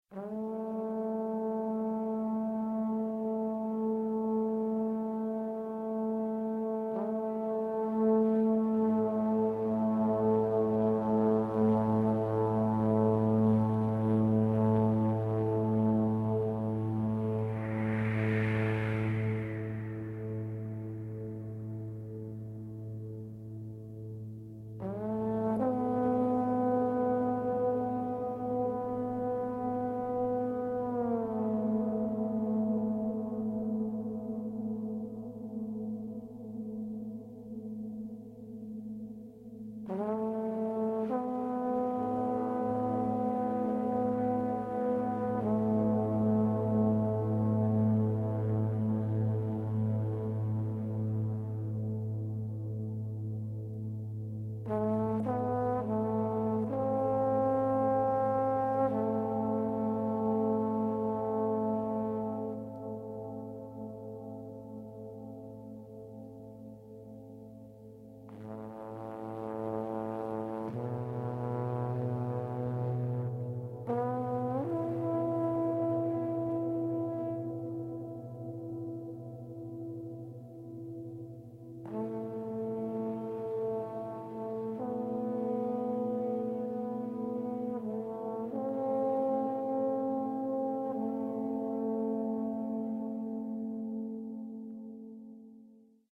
trombones